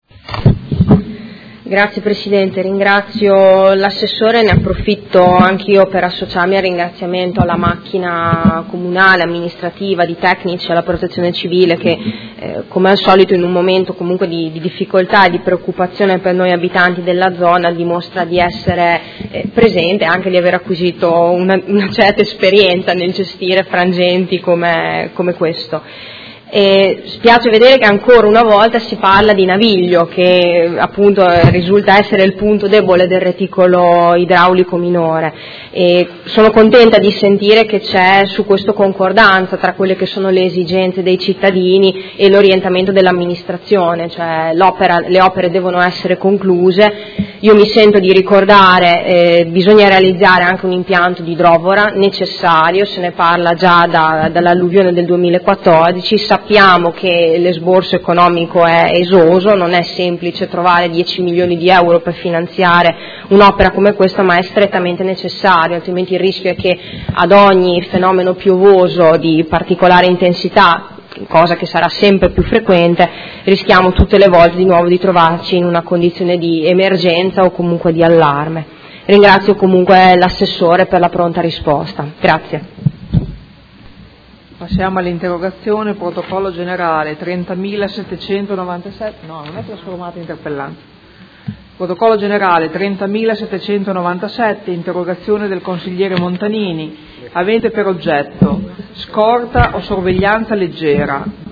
Seduta del 10/03/2016. Conclude interrogazione Urgente dei Consiglieri Morini e Stella (P.D.) avente per oggetto: Situazione del nodo idraulico ed effetti delle piogge intense